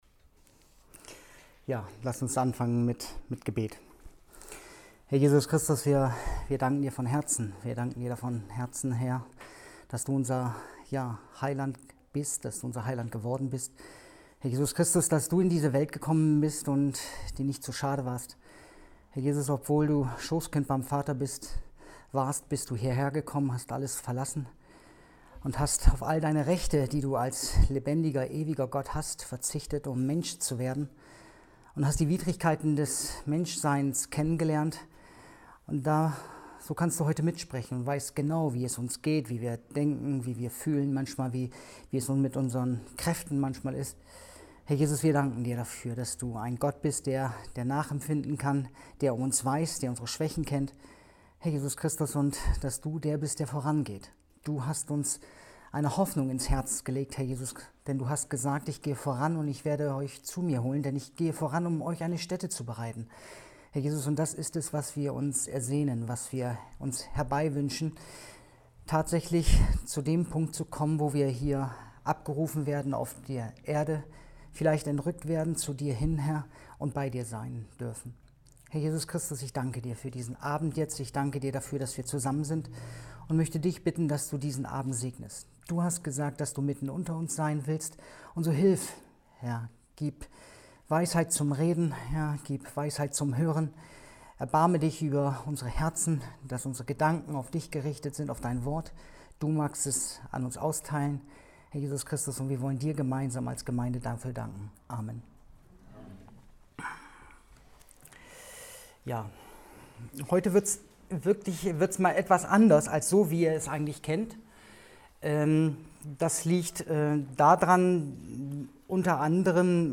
Bibelstunde_25.06.2020